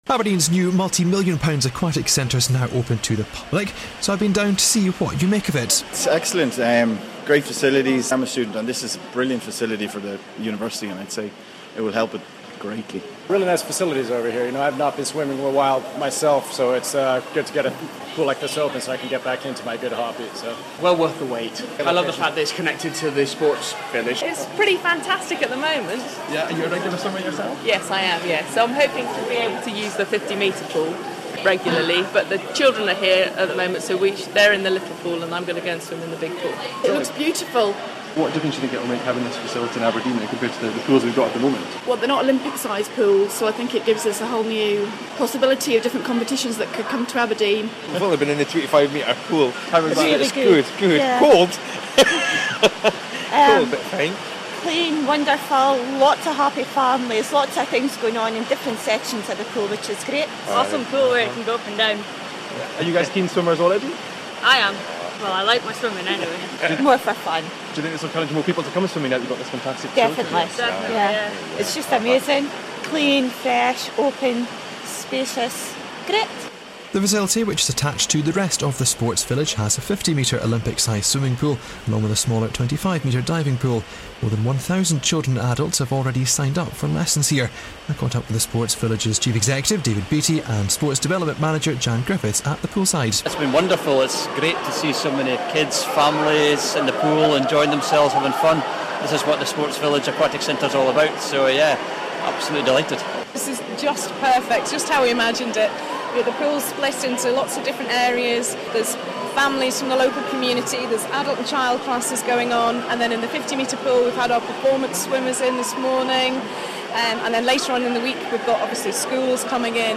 NEWS: Aberdeen Aquatics Centre Opens To Public